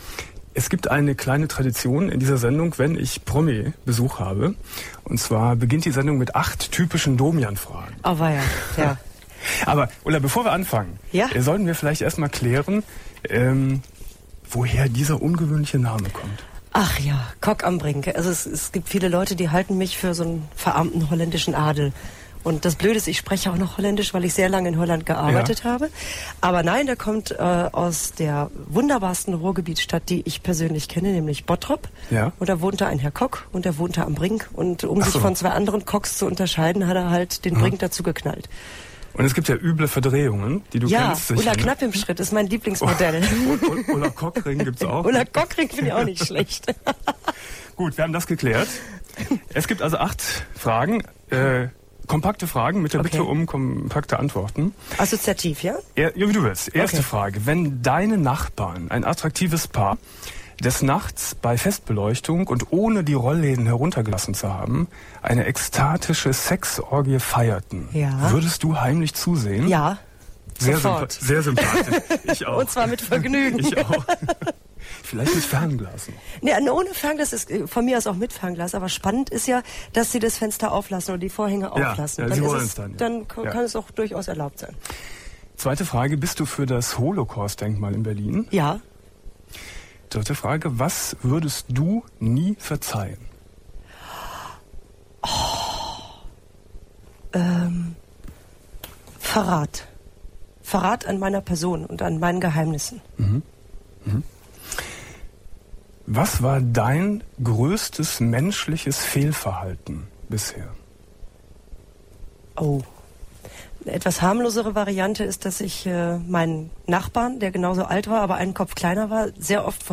25.03.2000 Domian Studiogast: Ulla Kock am Brink ~ Domian Talkradio - Das Archiv Podcast